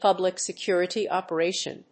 public+security+operation.mp3